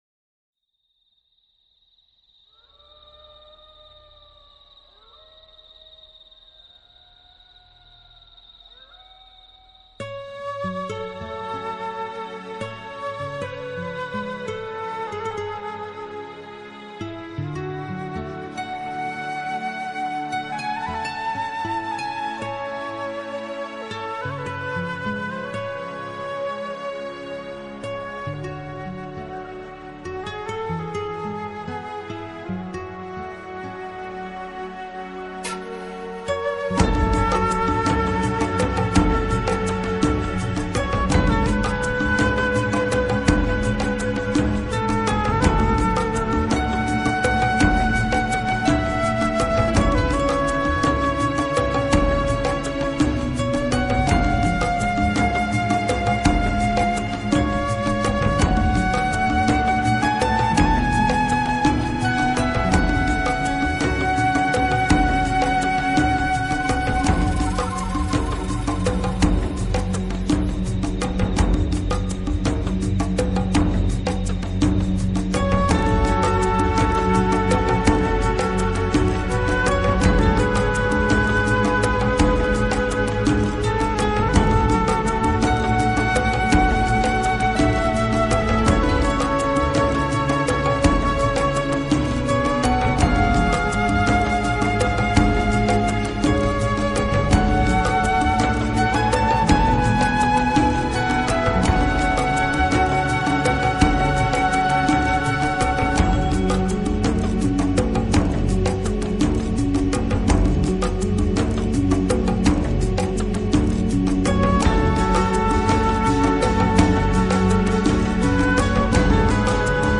他的长笛永远是最陶醉的乐器之一，略带涩音笛管里所发出的阵阵优美让人向往。
新世纪音乐